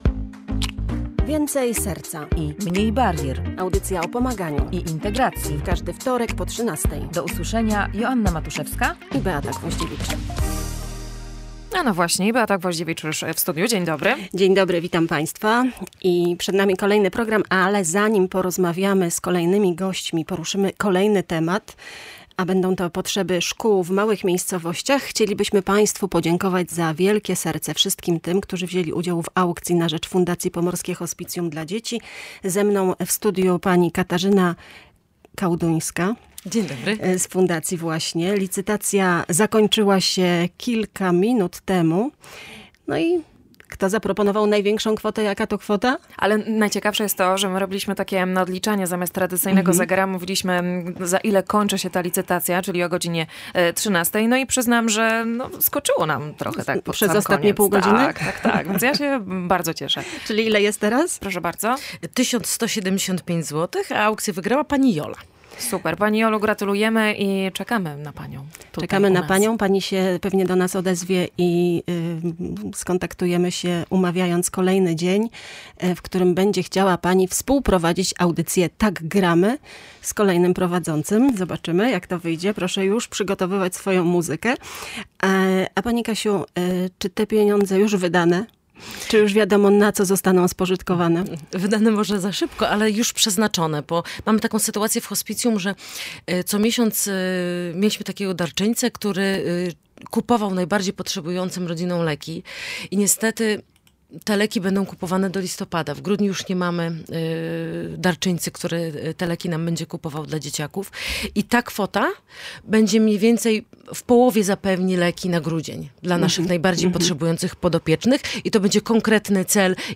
Szkoły w małych miejscowościach to więcej niż placówki oświatowe. Są to ośrodki aktywizujące lokalną społeczność, które pełnią ważną rolę w kreowaniu inicjatyw kulturalnych i społecznych oraz budują światopogląd uczniów – tłumaczą goście audycji Więcej serca i mniej barier.